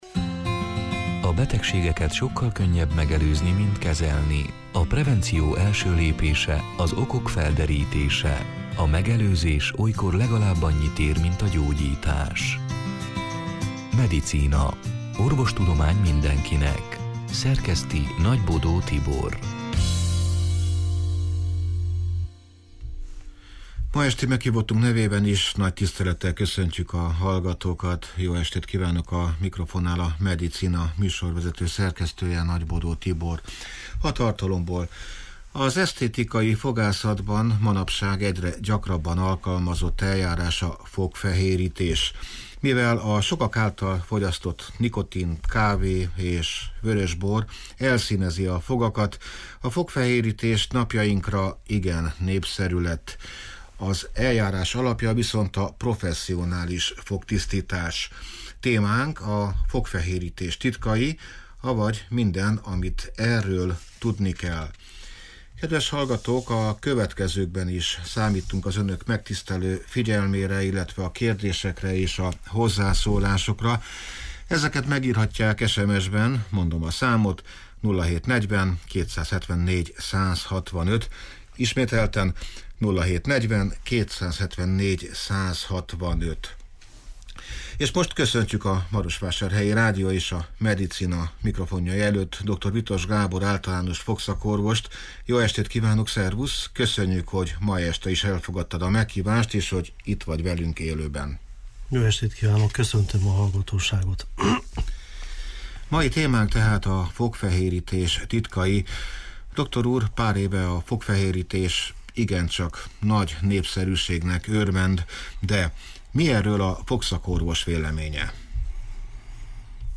(elhangzott: 2023. február 8-án, szerdán este nyolc órától élőben)